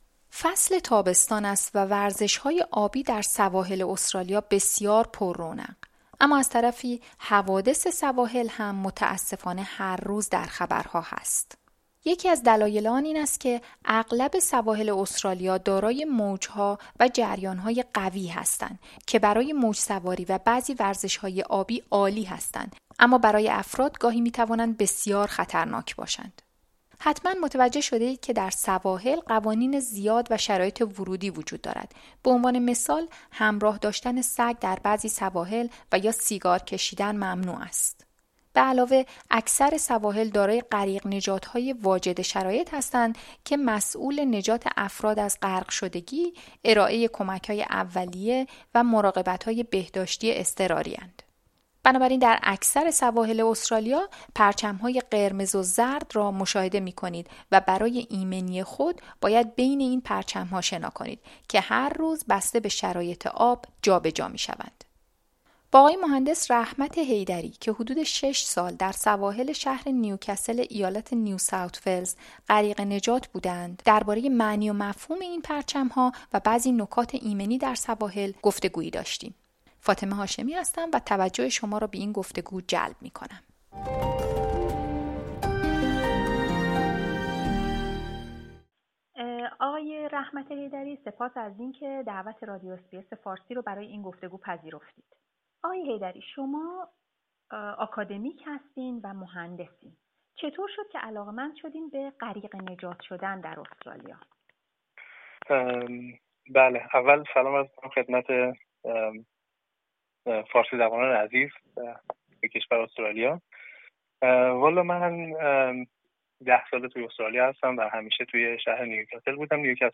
درباره معنی و مفهوم این پرچم‌ها و بعضی نکات ایمنی در سواحل گفتگویی داشتیم .